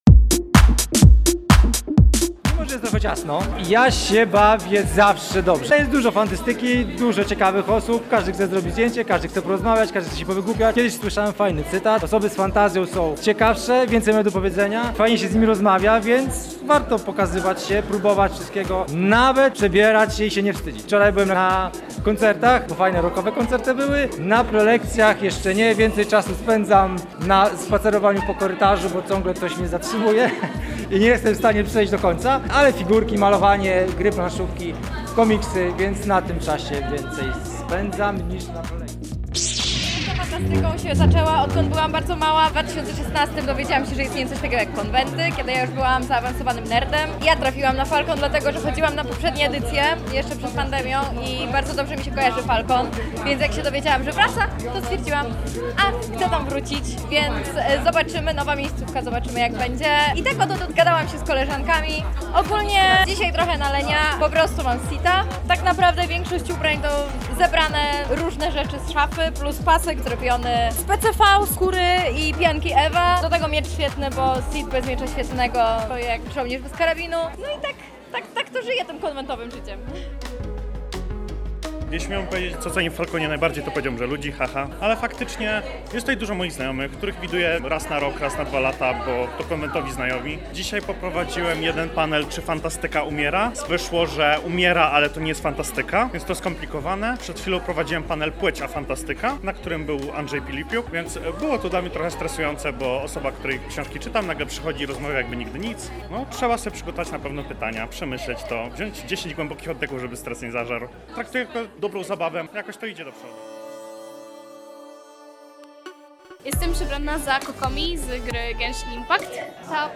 Posłuchajmy co opowiedzieli nam o swoich wrażeniach.